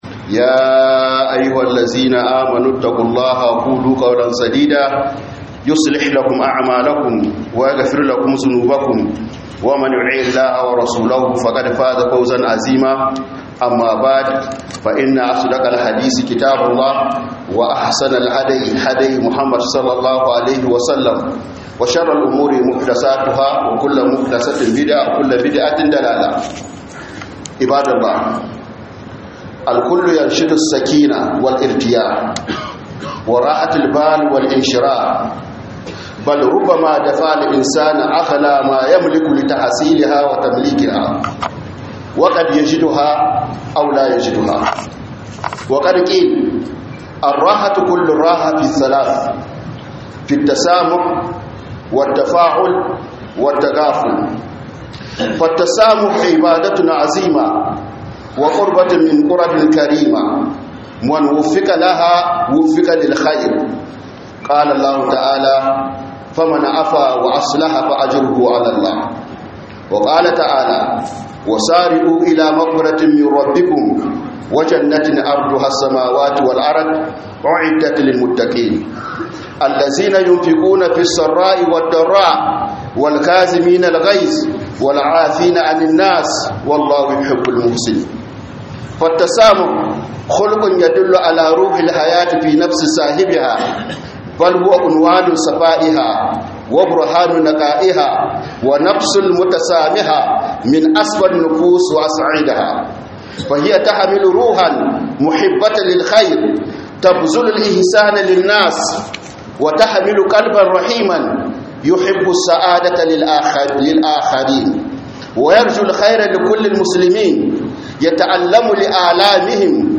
Huduba kan kunciyar hankali cikin abu uku - HUDUBA